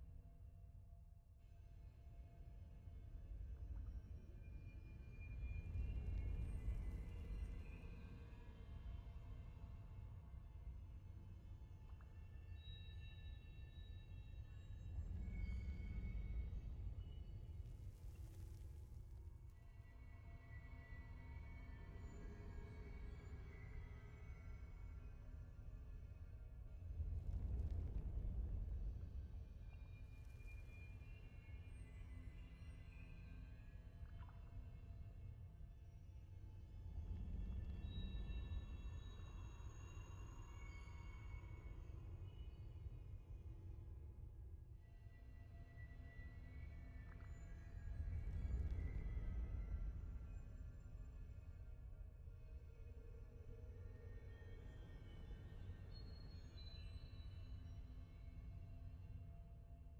sfx-ambience-loop-tft-set17.ogg